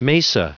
Prononciation du mot mesa en anglais (fichier audio)
Prononciation du mot : mesa